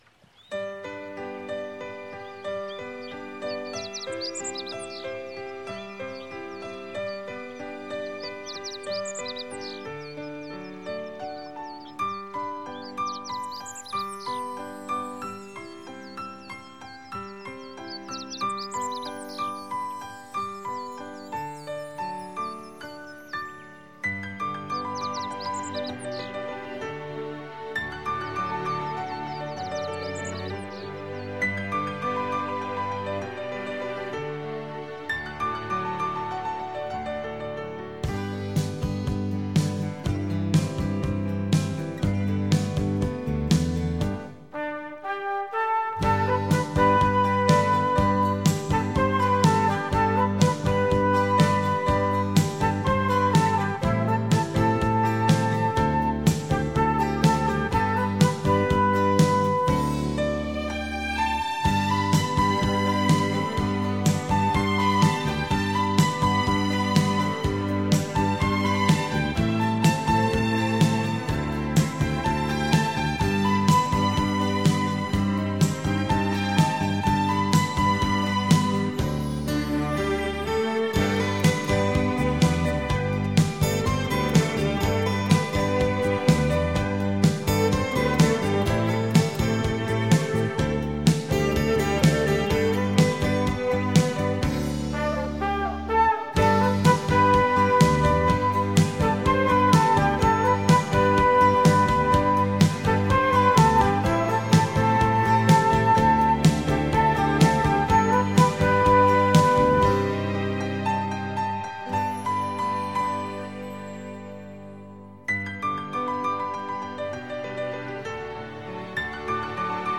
清新的晨乐
そんなさわやかな朝のための音楽です。